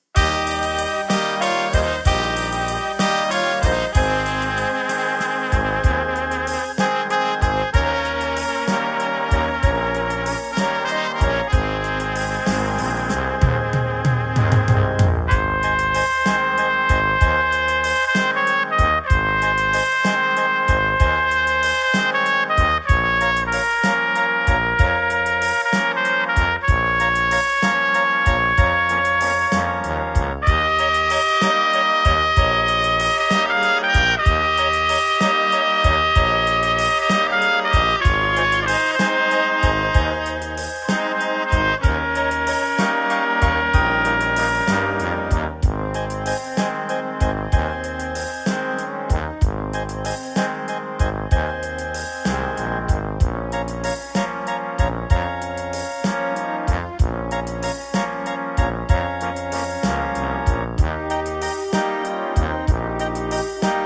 CD-čko obsahuje 12 lidových koled a vánočních písní,